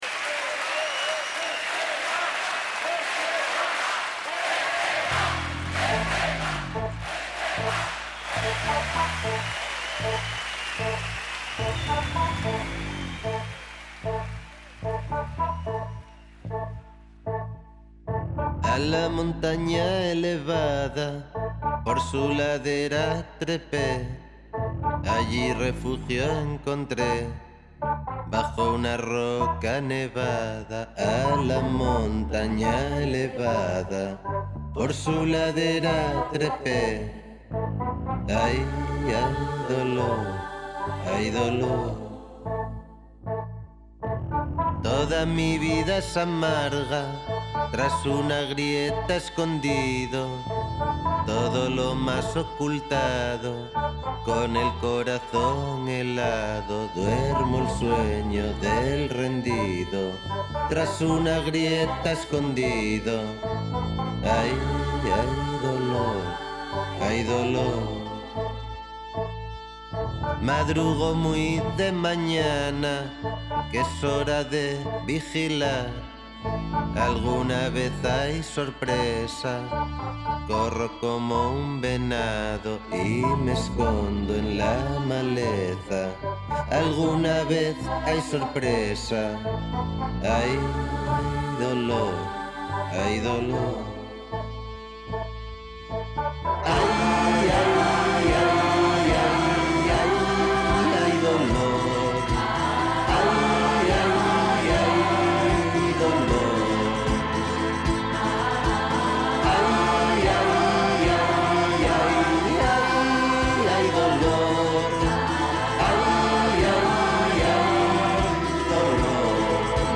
Naiz irratiko saio musikala. Euskal Herriko musikariek txandaka gidatutako saioa. Kantu aukeraketa bat.